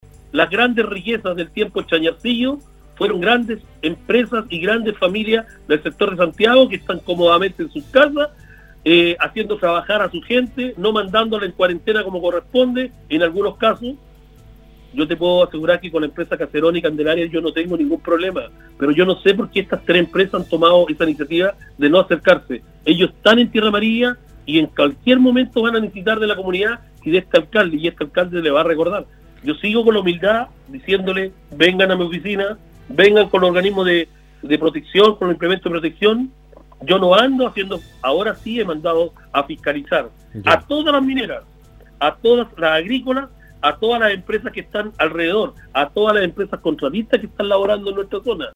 La mañana de este jueves, el alcalde de Tierra Amarilla, Mario Morales tuvo un nuevo contacto con Nostálgica, donde se refirió a la situación de su comuna con respecto a los casos de COVID-19, abordando además otros temas, ligados a la contingencia sanitaria, apuntando esta vez hacia algunas empresas mineras y las agrícolas que están instaladas en Tierra Amarilla: